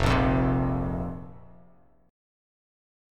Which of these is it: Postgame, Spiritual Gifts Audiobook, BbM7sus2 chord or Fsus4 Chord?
Fsus4 Chord